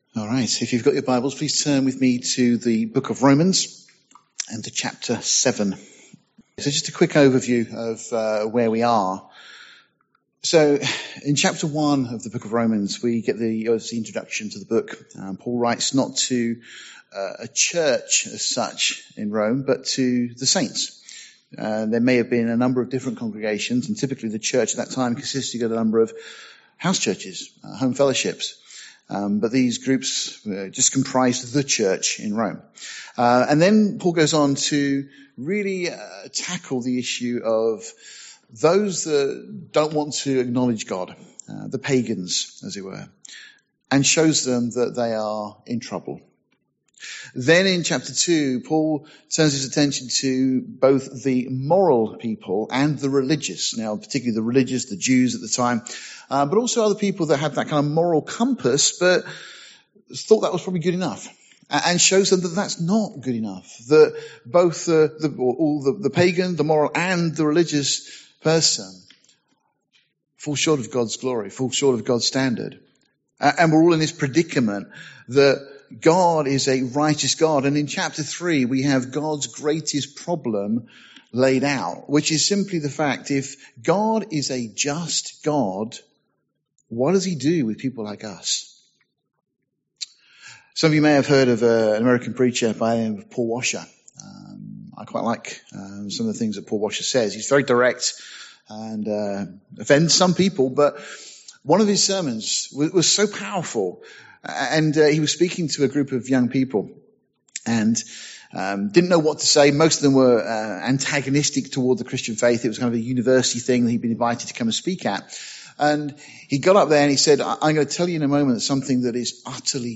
In this verse-by-verse study of Romans chapter 7